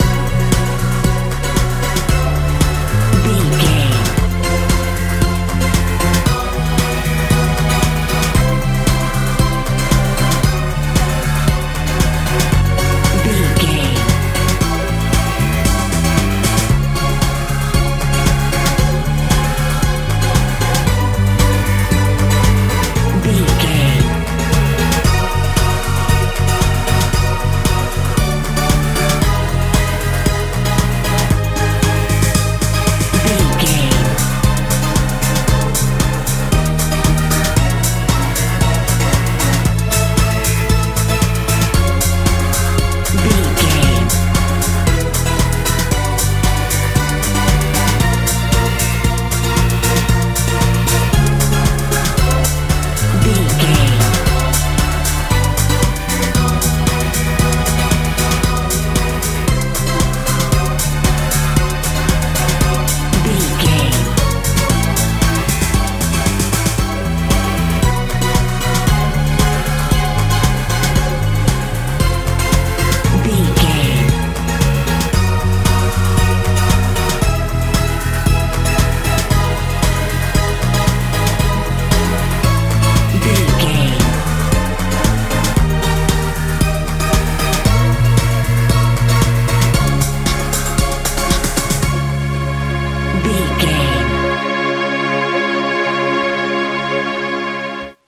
dance feel
Ionian/Major
energetic
dreamy
synthesiser
bass guitar
drums
80s
90s
heavy